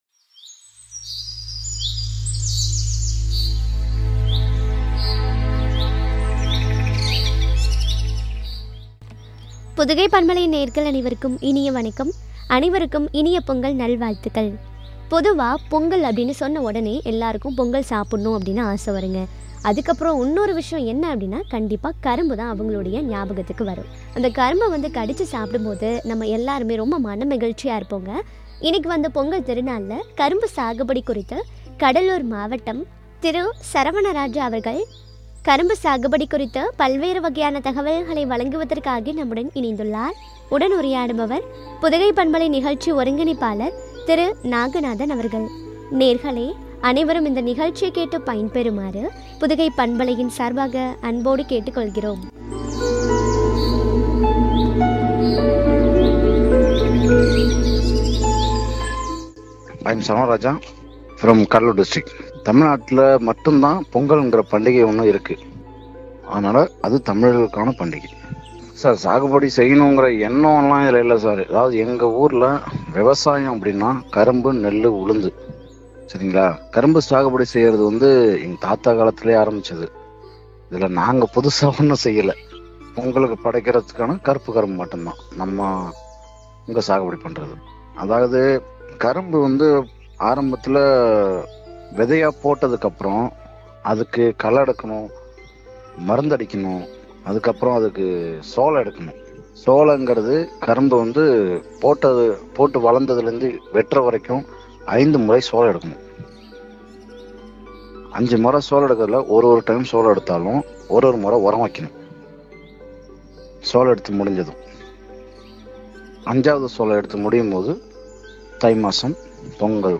“அமிர்தினும் இனிய பண்டிகை” குறித்து வழங்கிய உரையாடல்.